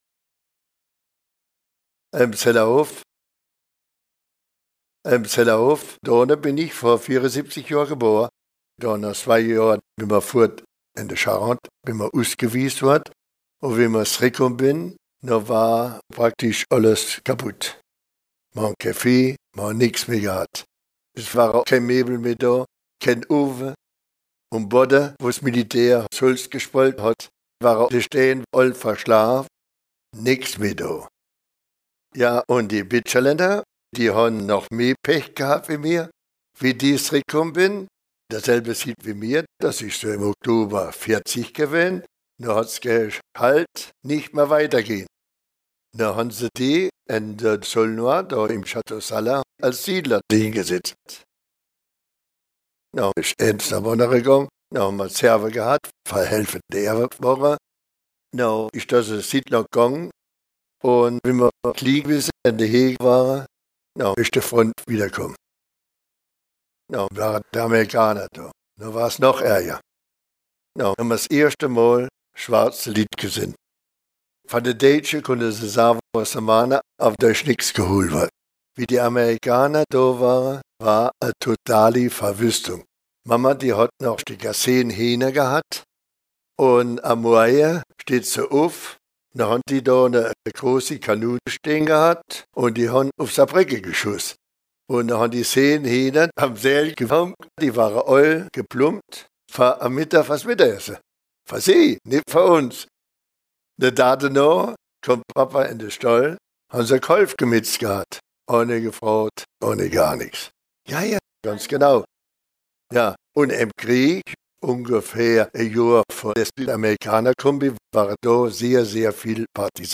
Contes et récits en ditsch enregistrés dans les communes de Linstroff, Grostenquin, Bistroff, Erstroff, Gréning, Freybouse, Petit Tenquin-Encheville, Petit Tenquin et Hellimer-Grostenquin.